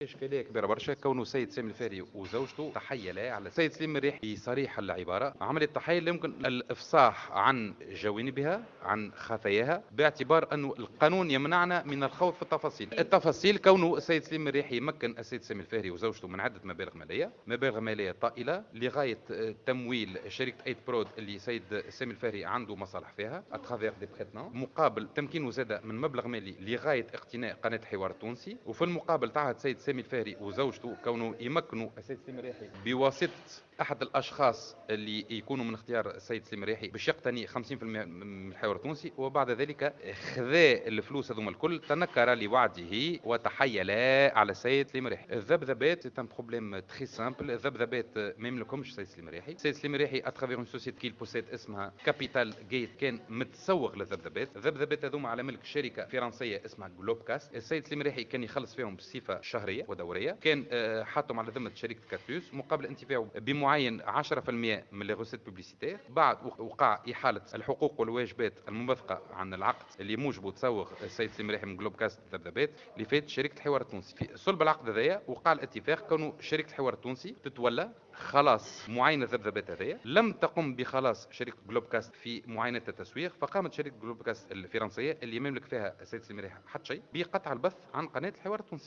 خلال ندوة صحفية